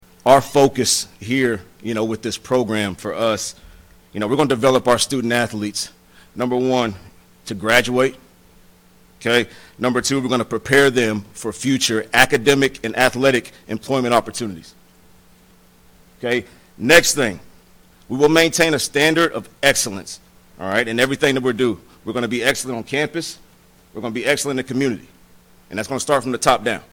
The press conference was followed by a meet and greet.